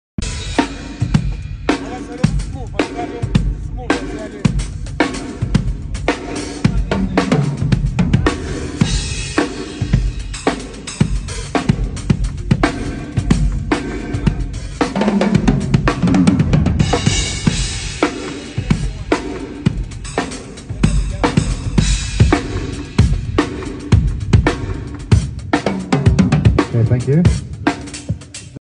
Drum Groove That Will Make sound effects free download
Listen to the reverb on that snare 🔥 We don't know who the drummer is.